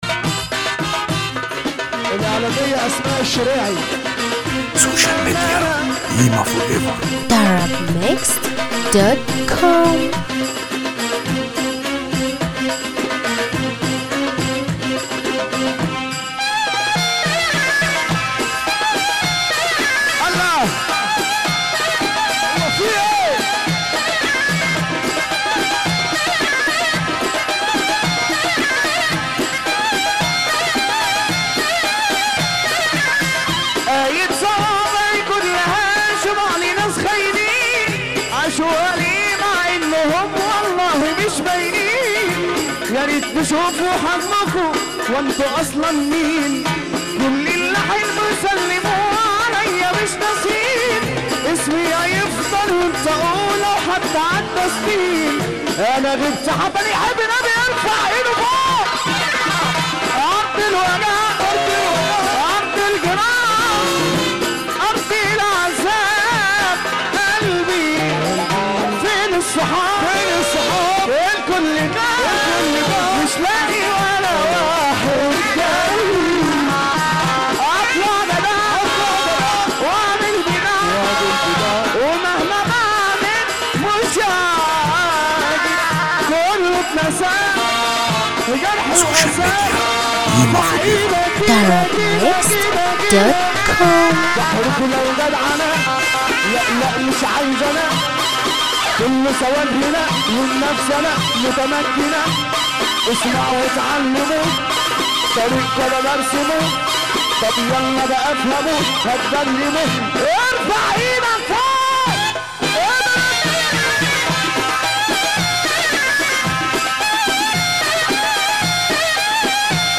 موال
بشكل حزين جدا